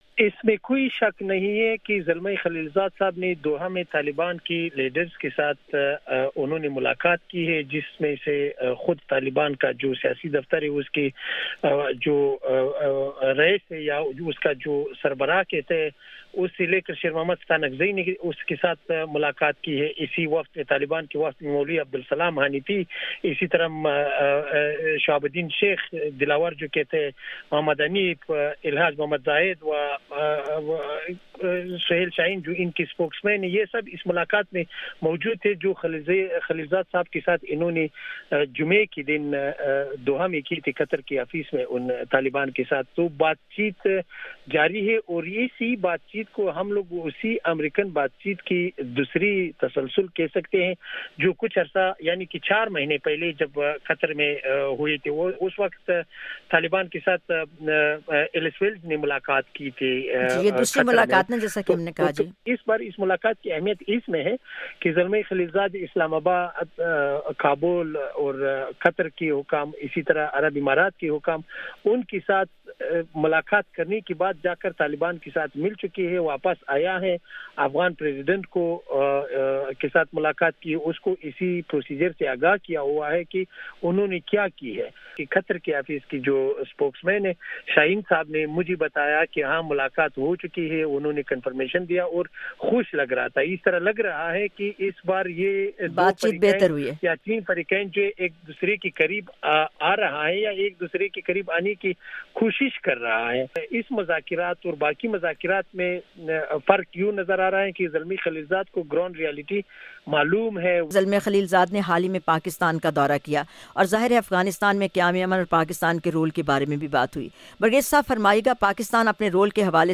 Jahan Rang discussion, 'Khalilzad's meetings with Afghan Taliban in Doha'